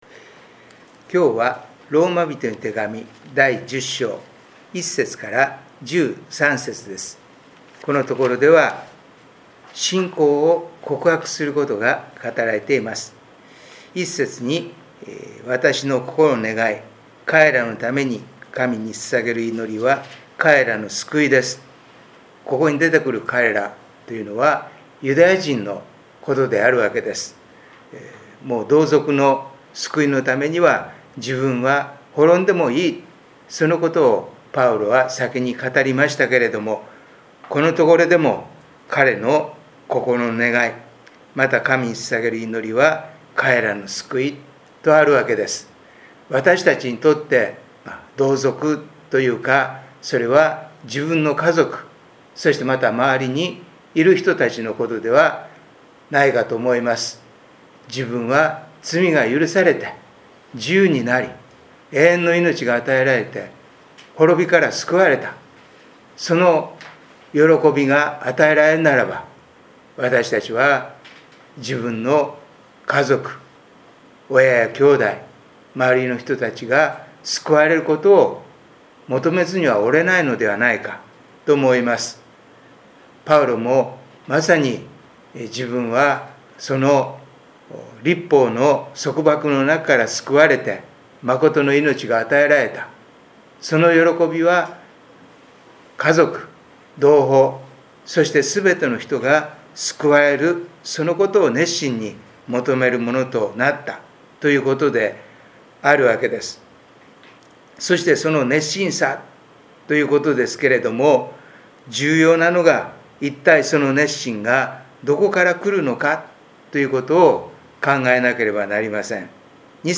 音声メッセージです。